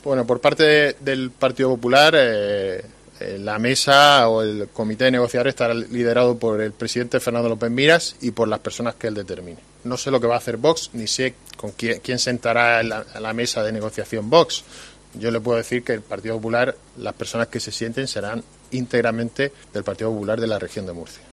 Joaquín Segado, portavoz Partido Popular sobre negociaciones con Vox